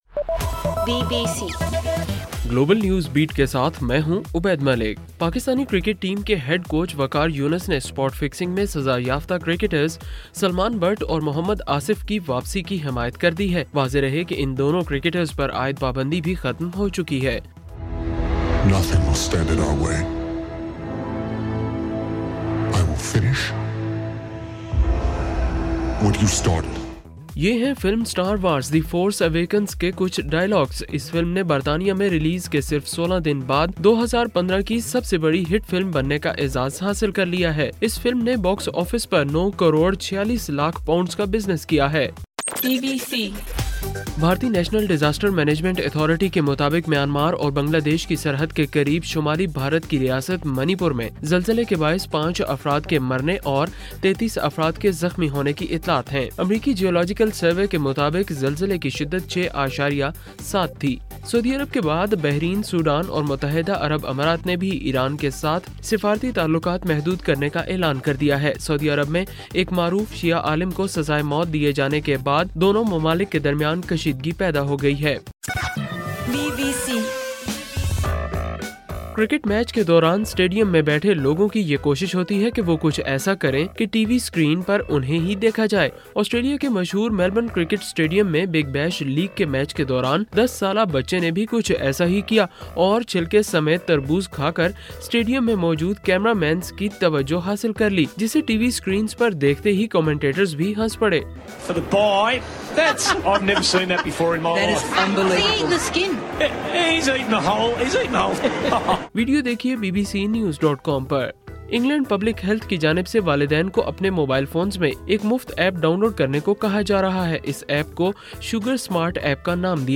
جنوری 04: رات 11 بجے کا گلوبل نیوز بیٹ بُلیٹن